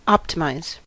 optimize: Wikimedia Commons US English Pronunciations
En-us-optimize.WAV